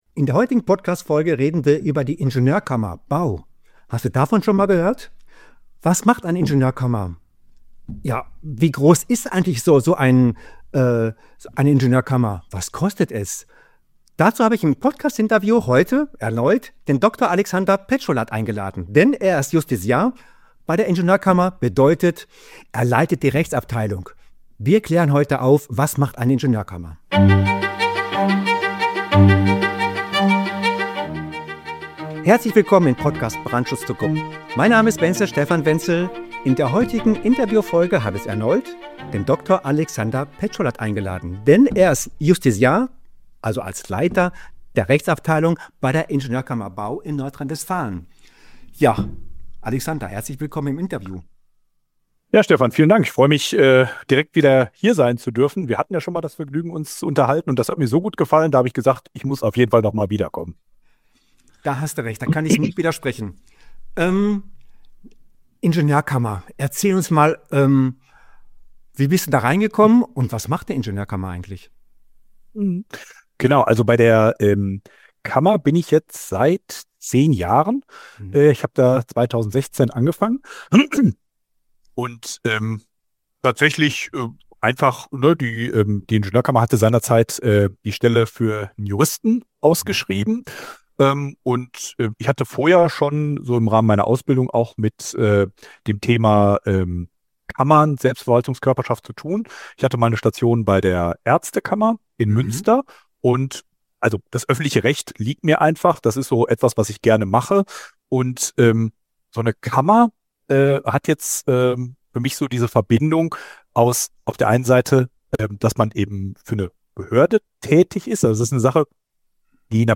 Und genau diese Einblicke bekommst du im Interview